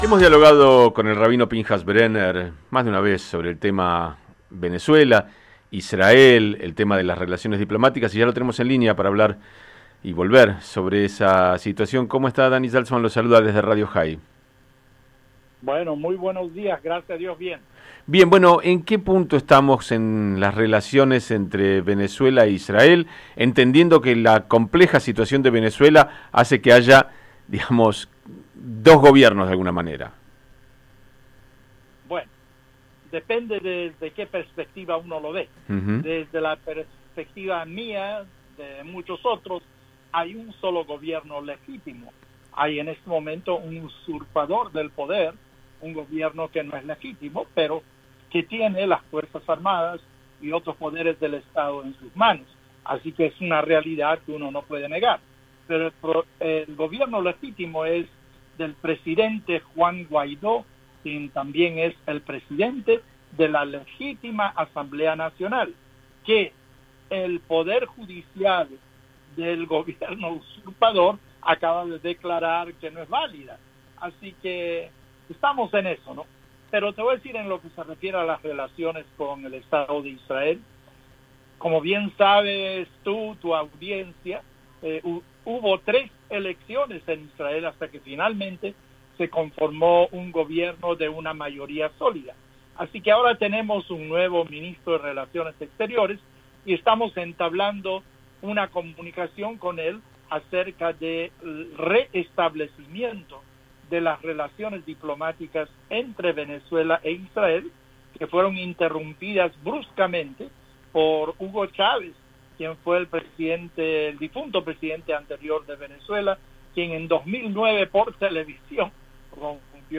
El rabino Pynchas Brener fue nombrado por Juan Guaidó como embajador de Venezuela en Israel, y dialogamos con él para saber en que punto está esa relación.